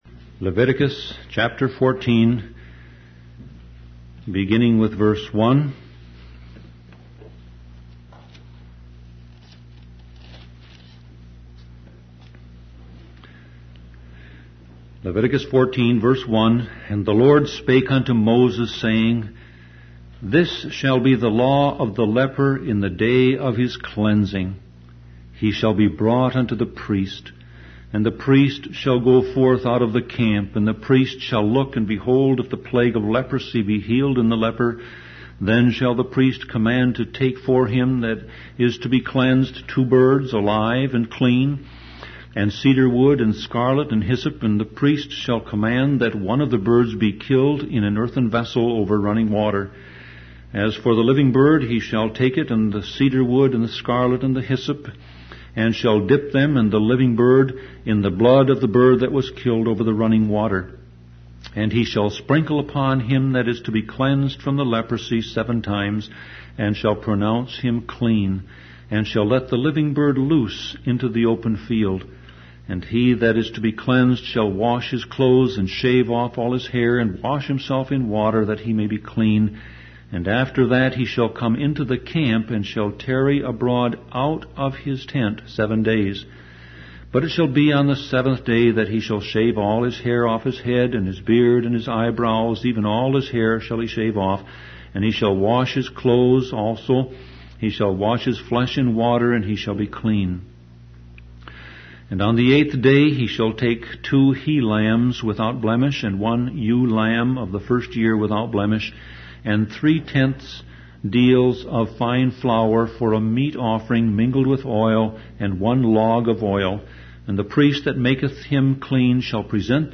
Sermon Audio Passage: Leviticus 14:1-32 Service Type